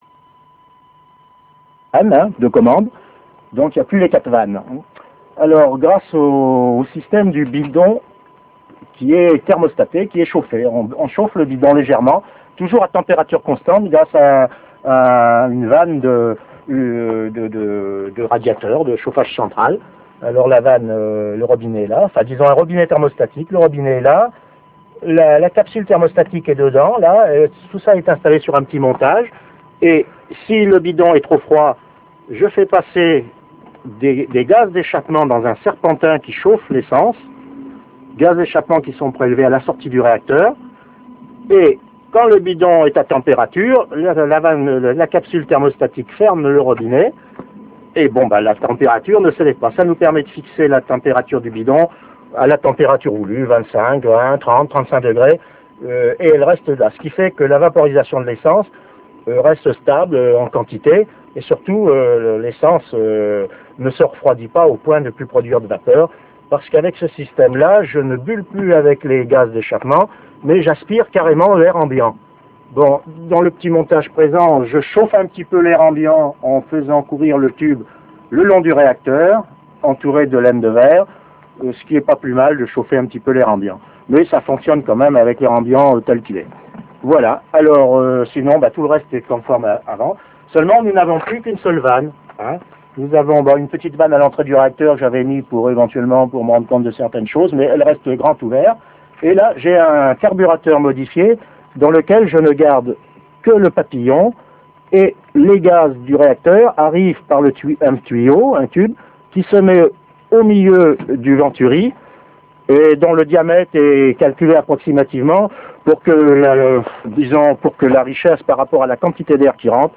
Moteur Honda 144 cm3, le second "rétrofitté".